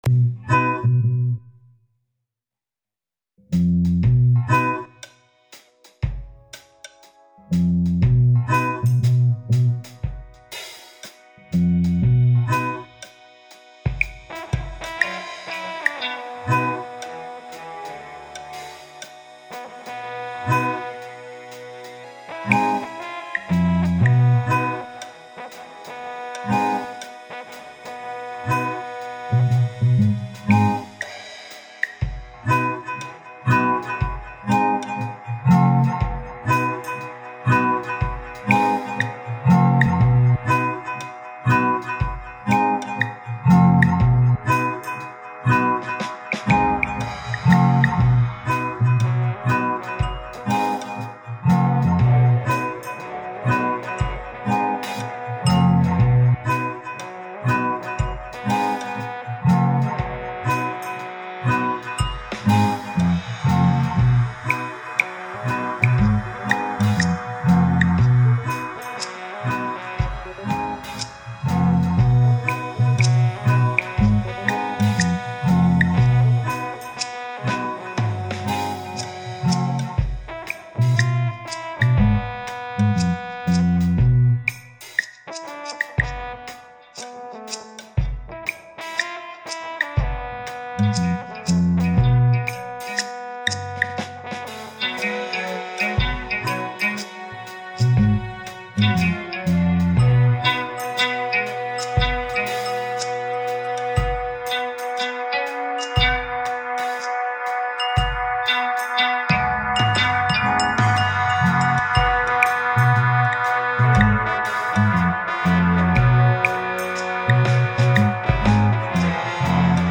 dance/electronic
Free Brazilian Home Made Indie Music
NuJazz
World music